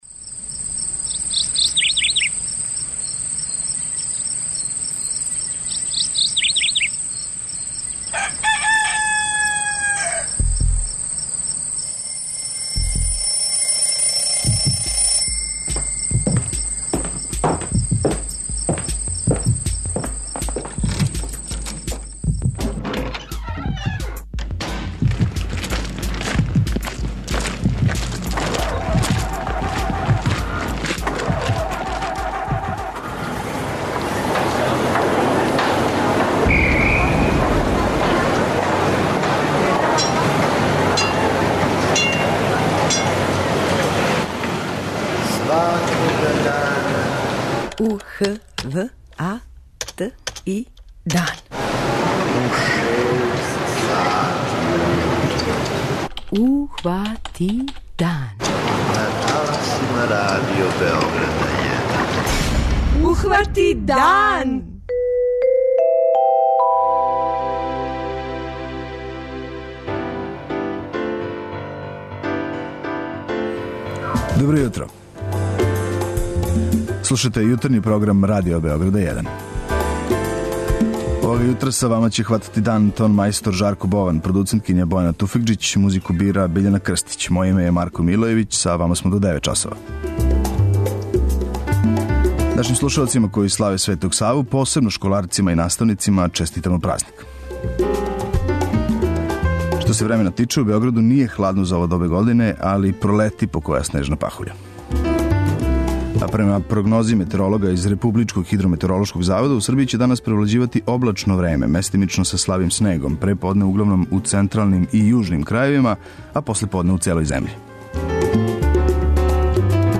преузми : 85.86 MB Ухвати дан Autor: Група аутора Јутарњи програм Радио Београда 1!